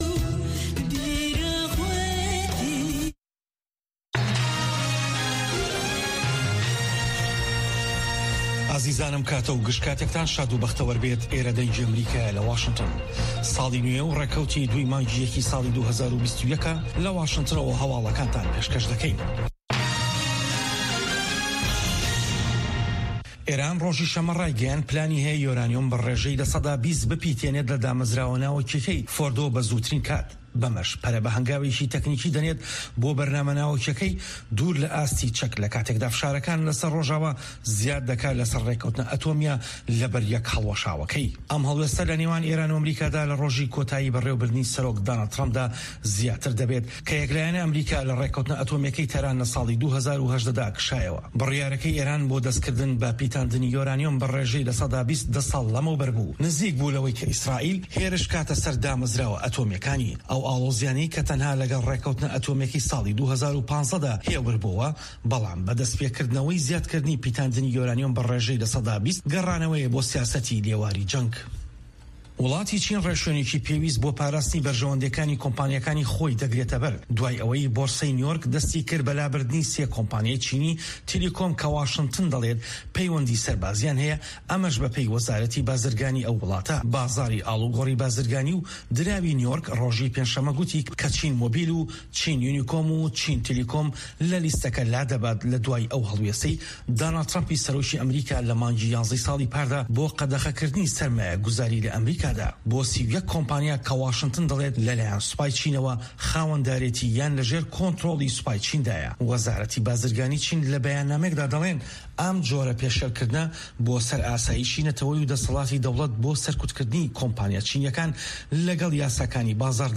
هه‌واڵه‌کان، ڕاپـۆرت، وتووێژ